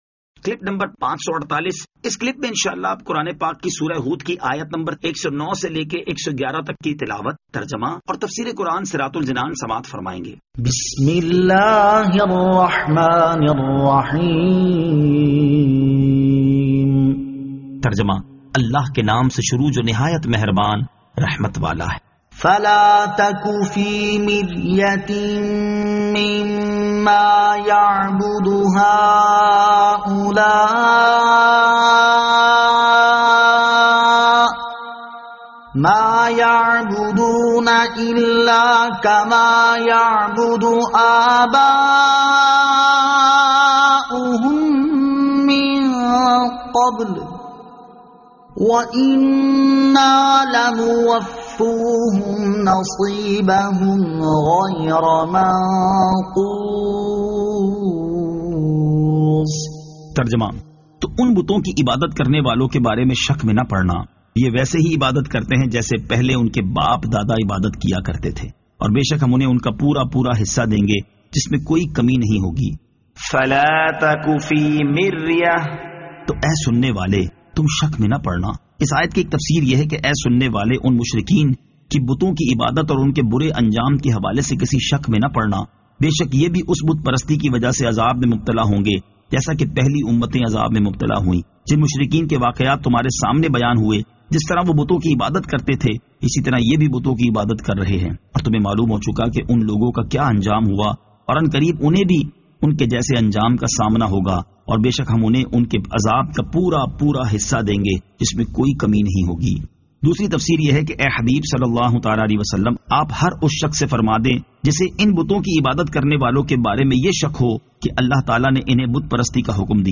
Surah Hud Ayat 109 To 111 Tilawat , Tarjama , Tafseer